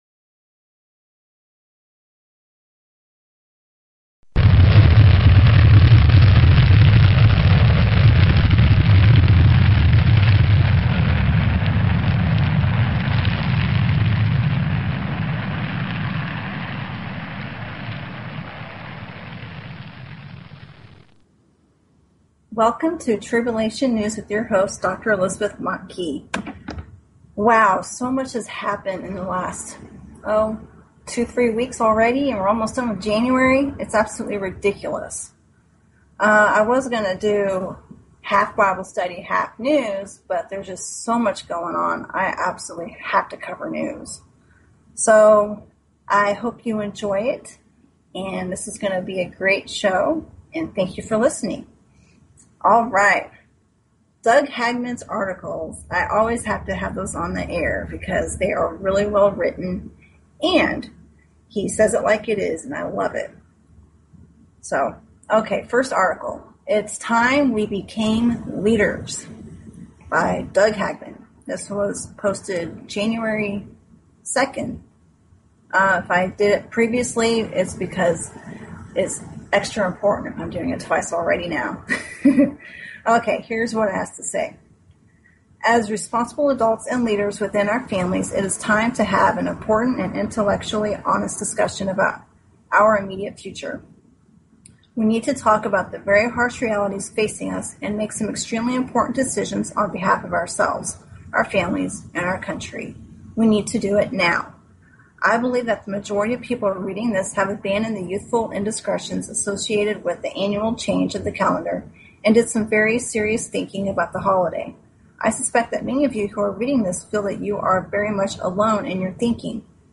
Talk Show Episode, Audio Podcast, Tribulation_News and Courtesy of BBS Radio on , show guests , about , categorized as